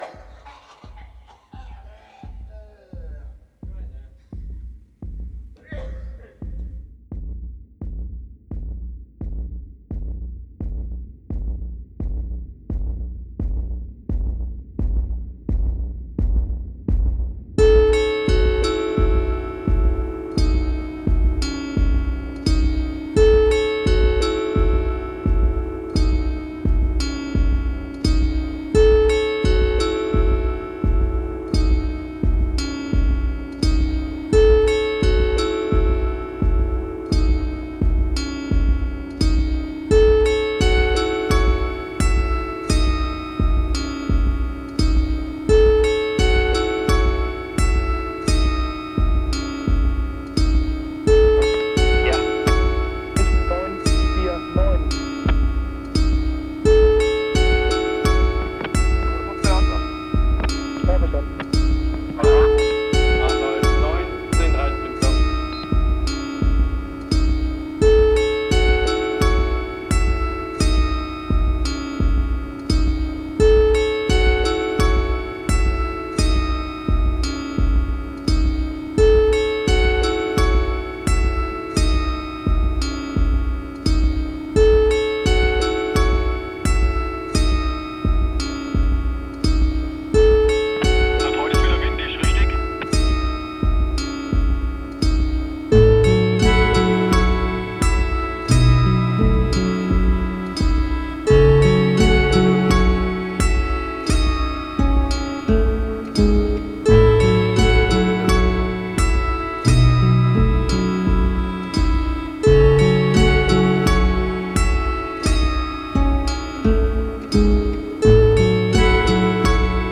post-punk experimentalists
the London-based four-piece